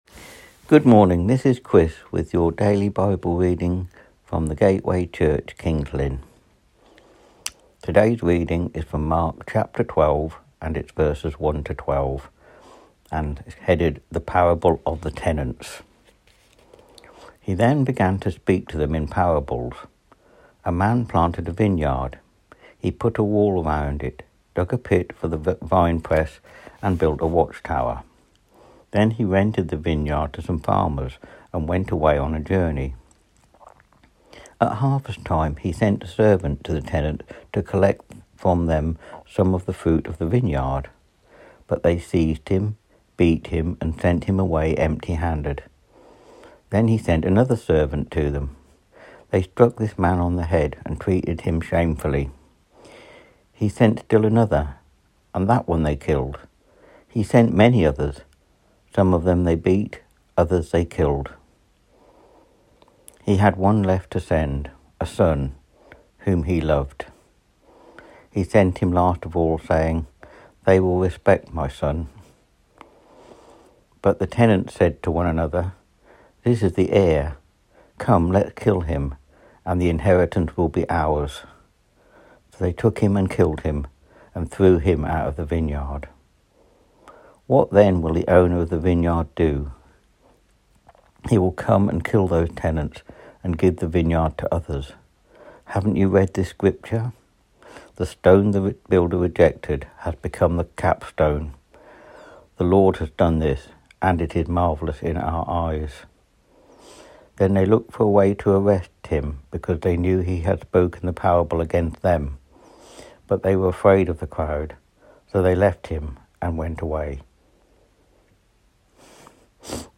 Today’s reading is from Mark 12:1-12